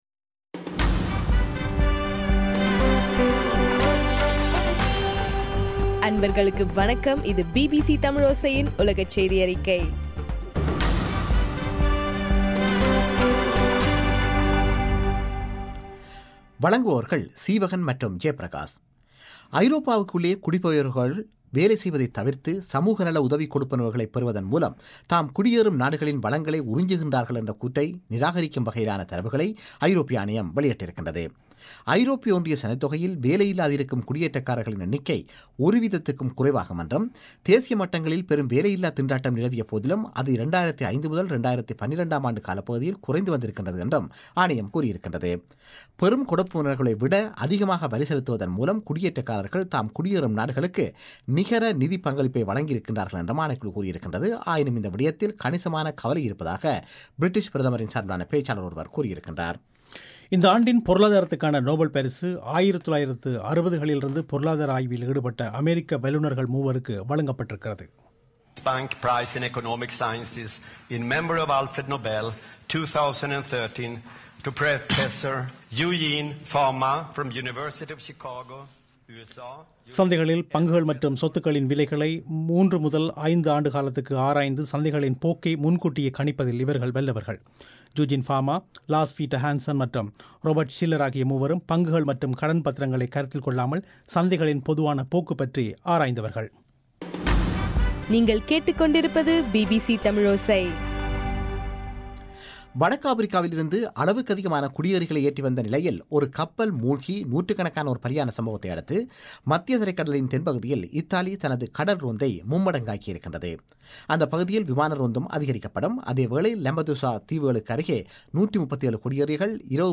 அக்டோபர் 14 தமிழோசை உலகச் செய்திகள்